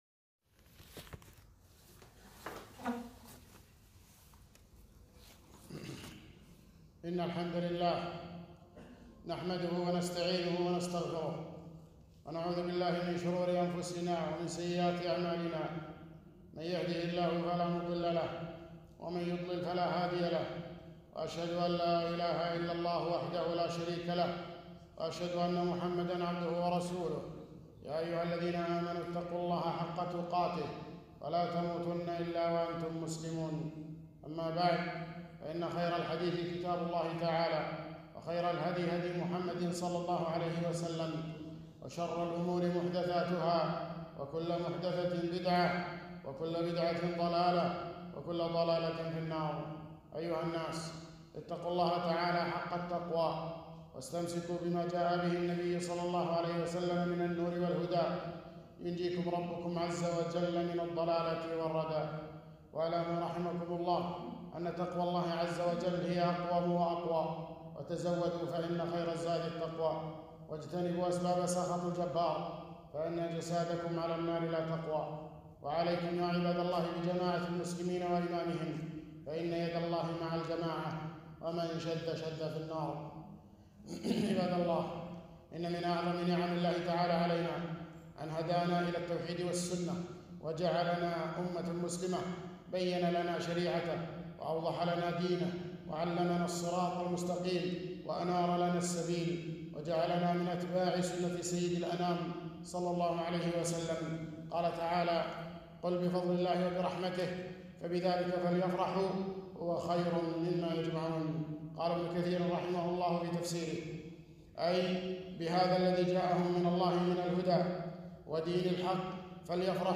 خطبة - التحذير من أمور دخيلة على المجتمع المسلم